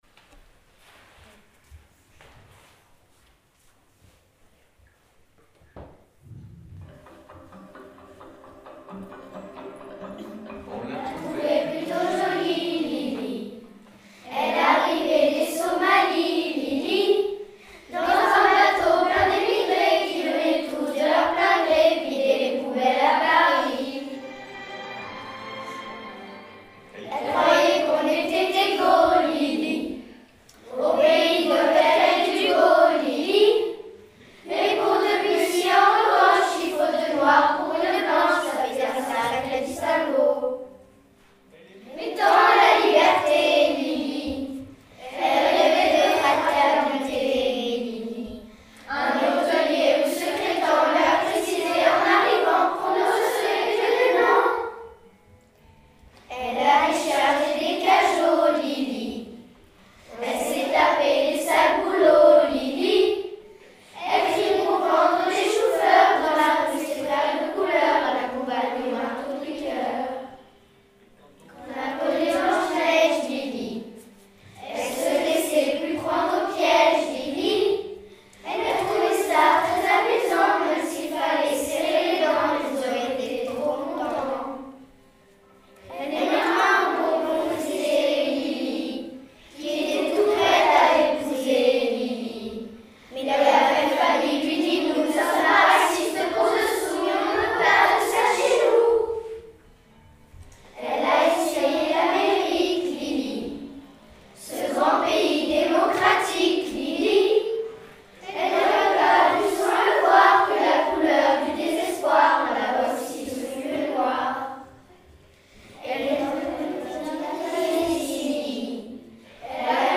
Chant des CM1 pour l’égalité des droits de l’homme